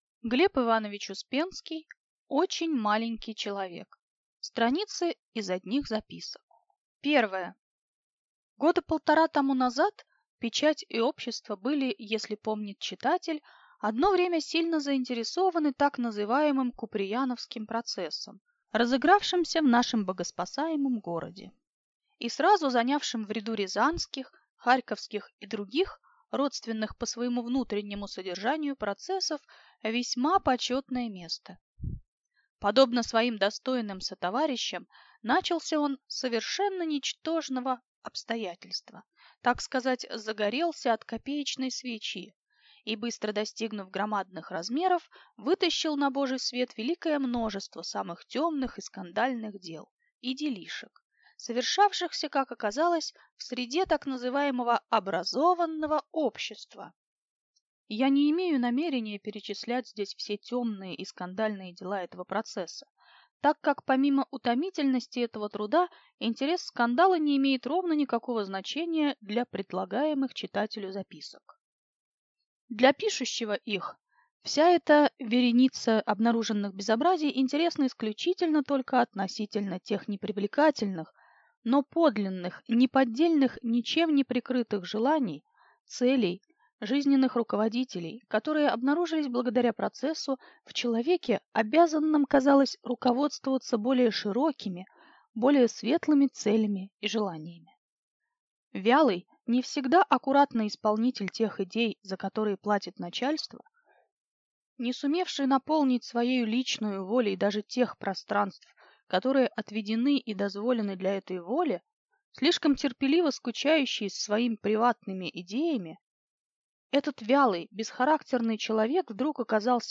Аудиокнига Очень маленький человек | Библиотека аудиокниг